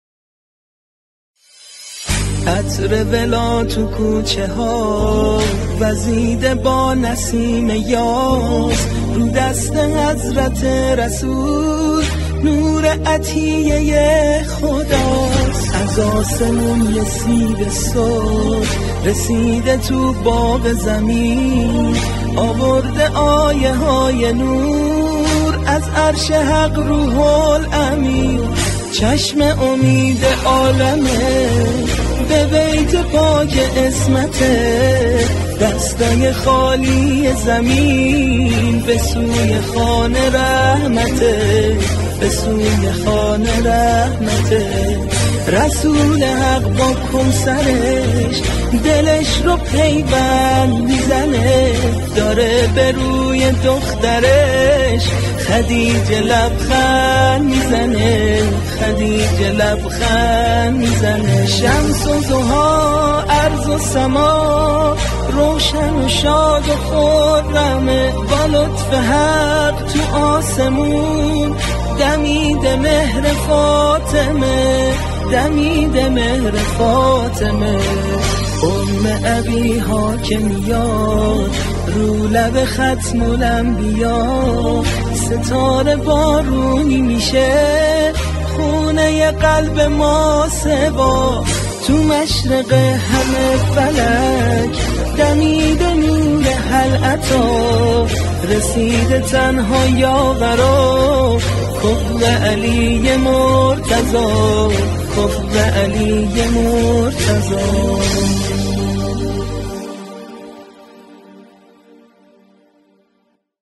مولودی حضرت زهرا
مداحی